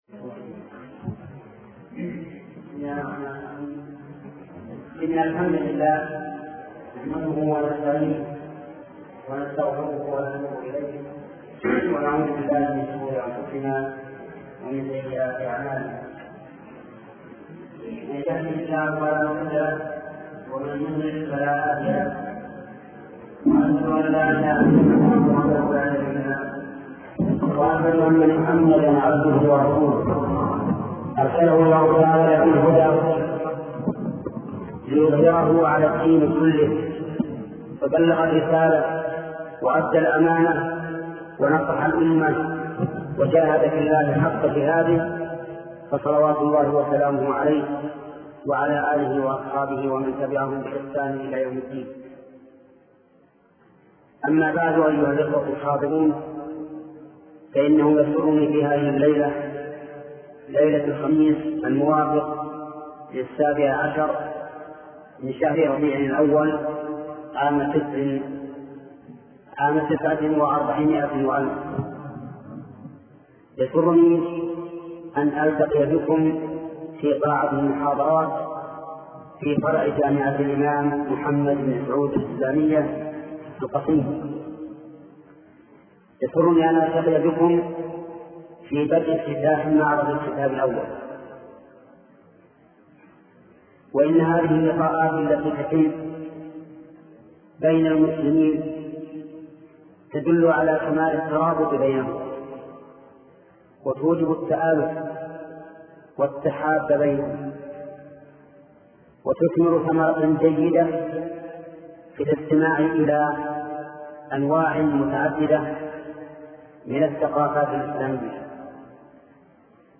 شبكة المعرفة الإسلامية | الدروس | أهمية الكتاب في حياة المسلم |محمد بن صالح العثيمين